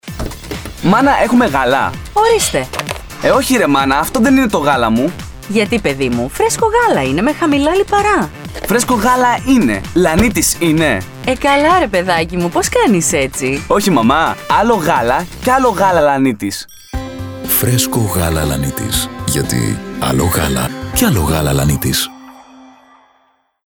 Professional Voice Artist and Voice Coach.
Sprechprobe: Sonstiges (Muttersprache):